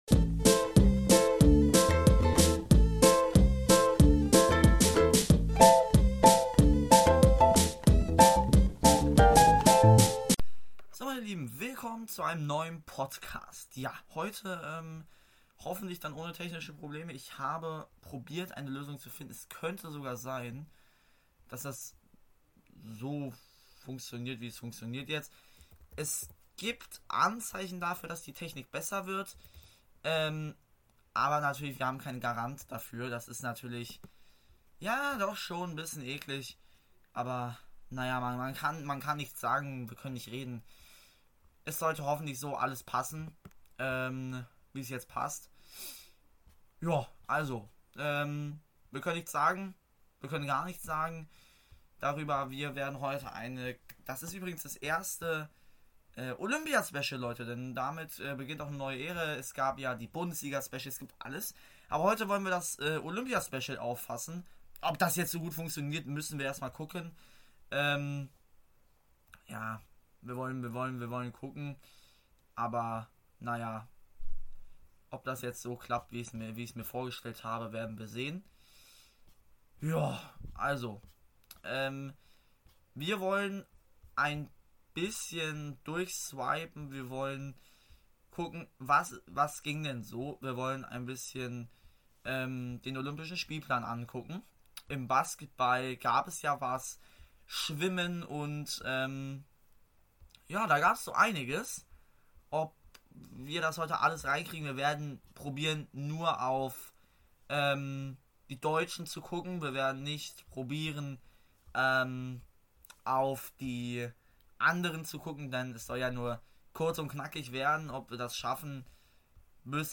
Hallo meine Lieben, heute ist die Technik endlich gut! Heute das erste Olympia-Special.